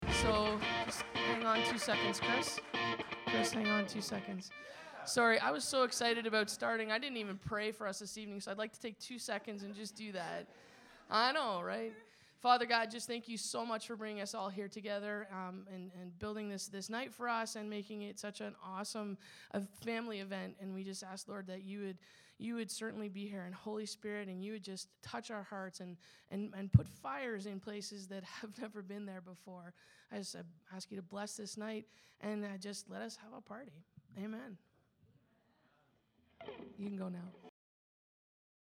In case you missed it or would just to listen again we’ve decided to share our soundboard recordings from last night’s Engage.
02 Prayer.mp3